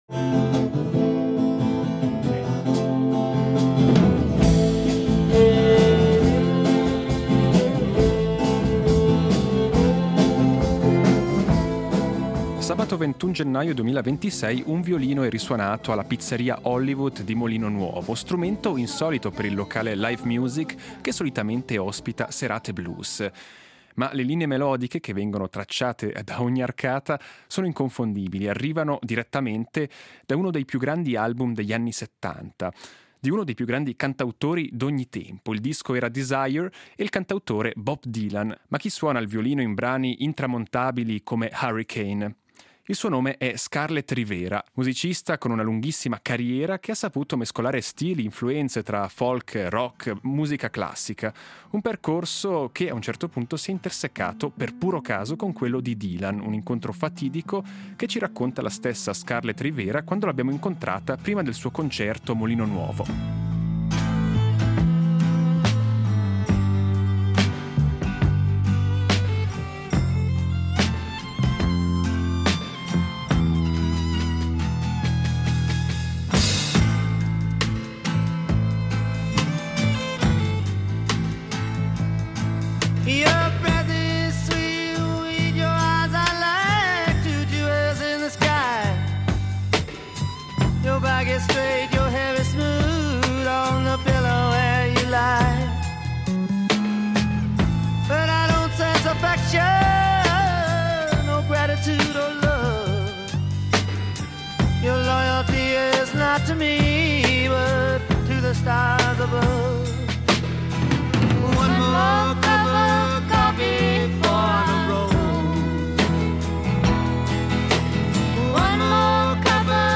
Incontro con Scarlet Rivera
Tra il sound-check e il concerto Scarlet Rivera ci ha concesso qualche minuto per ripercorrere alcune tappe della sua lunga ed emozionante carriera.